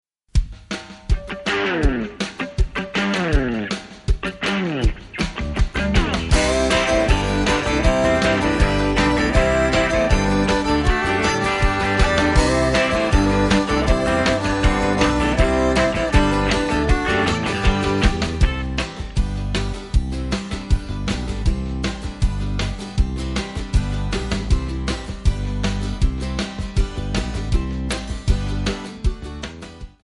F#
MPEG 1 Layer 3 (Stereo)
Backing track Karaoke
Country, 1990s